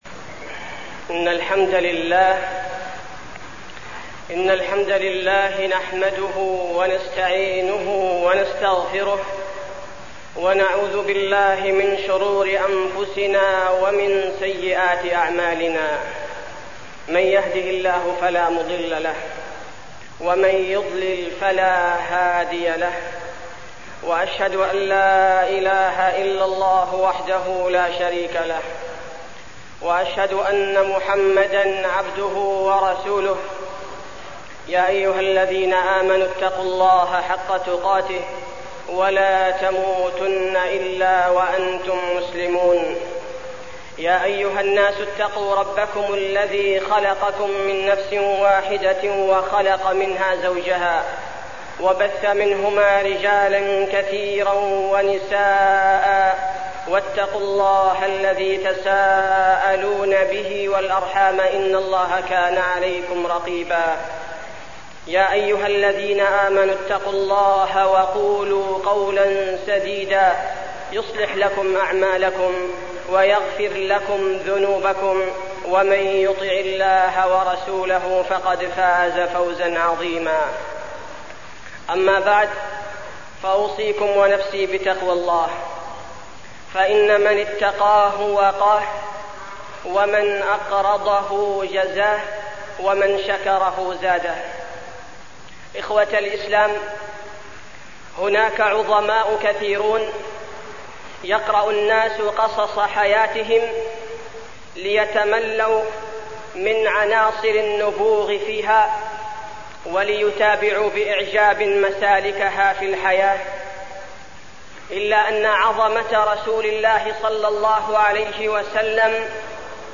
تاريخ النشر ٥ شعبان ١٤١٨ هـ المكان: المسجد النبوي الشيخ: فضيلة الشيخ عبدالباري الثبيتي فضيلة الشيخ عبدالباري الثبيتي أعظم العظماء الرسول صلى الله عليه وسلم